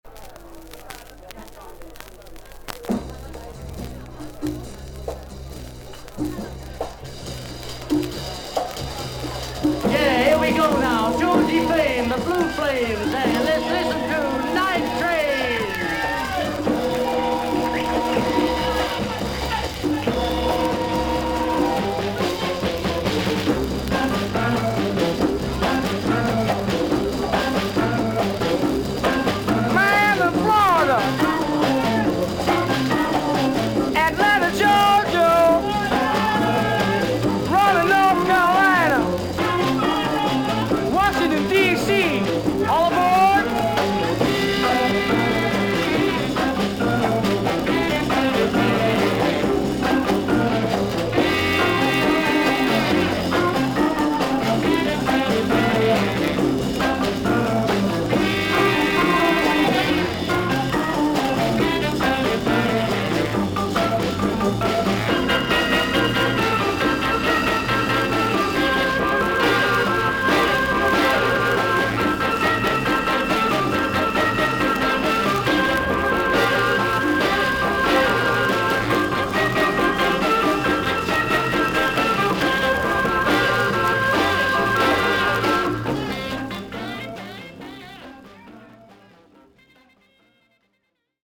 全体的に少々サーフィス・ノイズあり。少々軽いパチノイズの箇所あり。